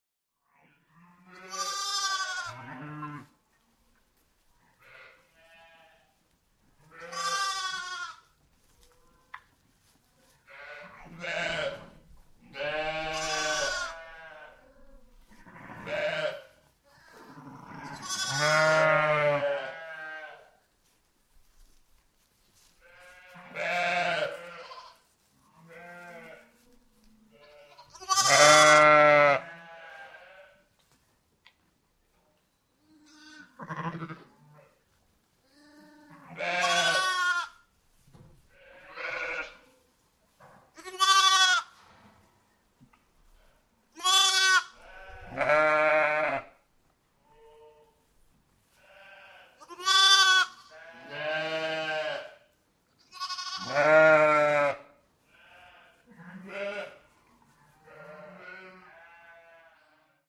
Звуки ягненка в овчарні: звук ягня для монтажу